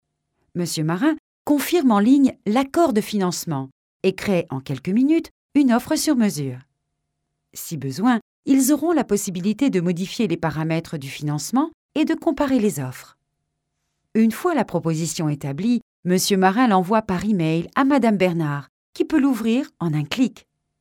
locutora francesa locutor